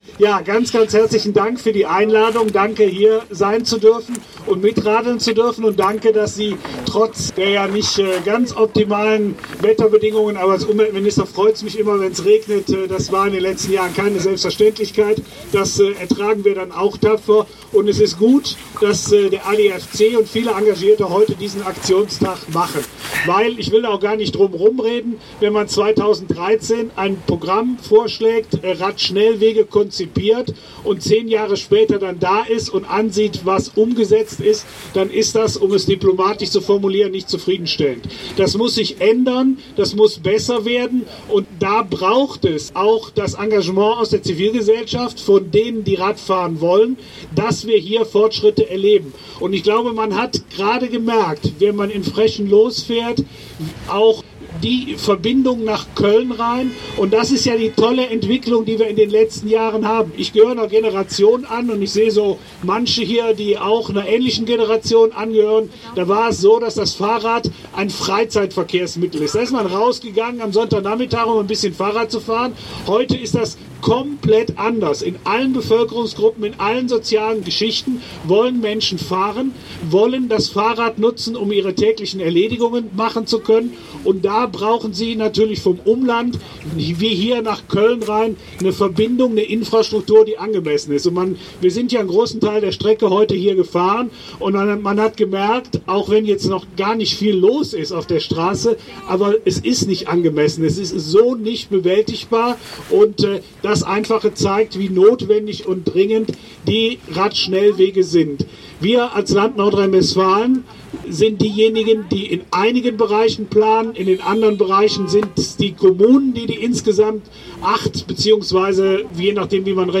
Kapitel 4: Abschlusskundgebung
NRW-Verkehrs- und Umweltminister Oliver Krischer zur Dringlichkeit des forcierten Radschnellwegeausbaus (Audio 6/9) [MP3]